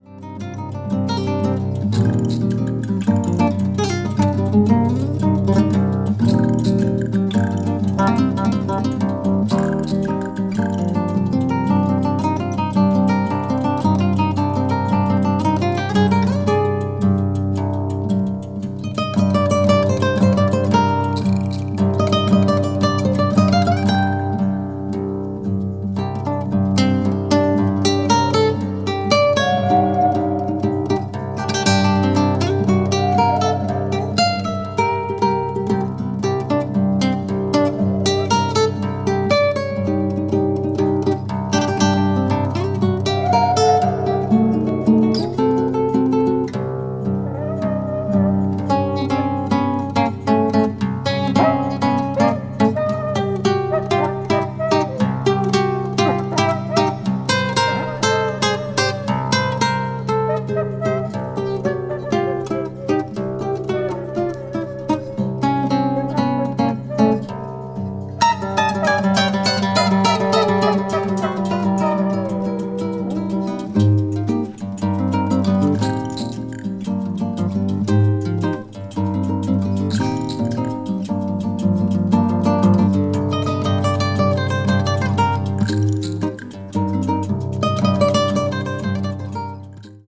media : EX+/EX+(some slightly noises.)
guitarist
(drums, percussion)
acoustic   bossa nova   brazil   choro   world music